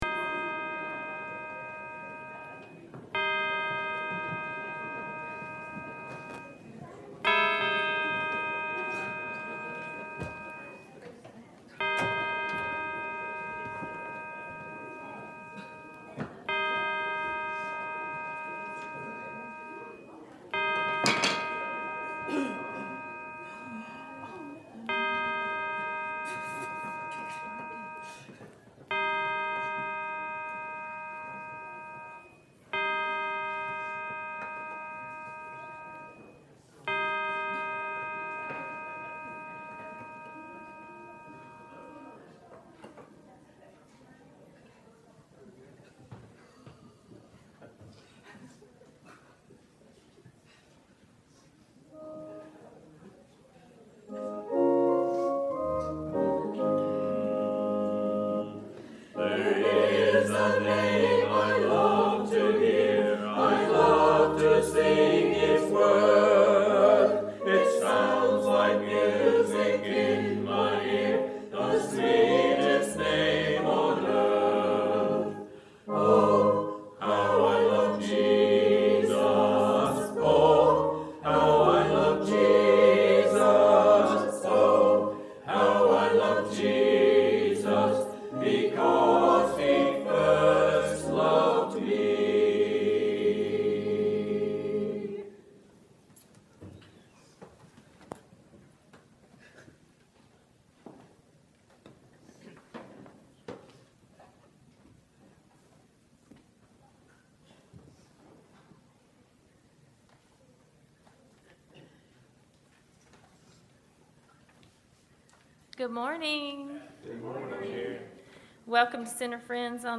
18 Service Type: Sunday Worship Topics